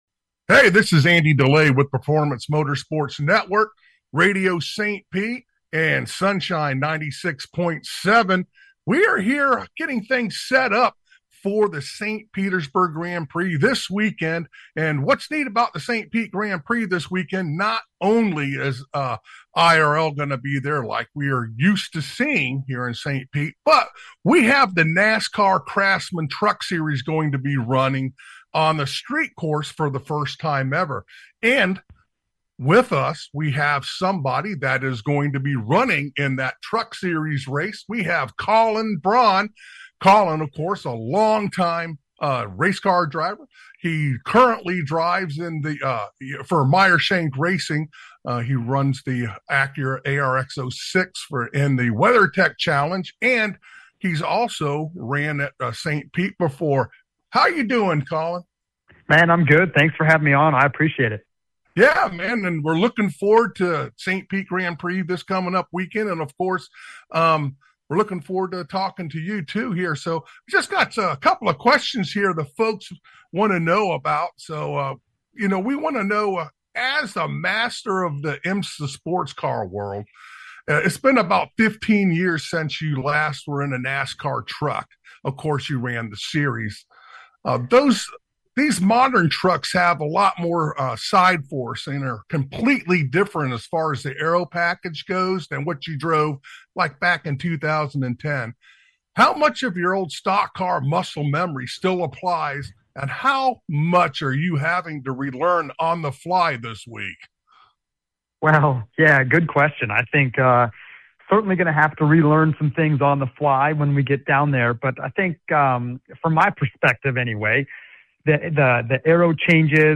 Champion Driver Colin Braun Interview 2-24-26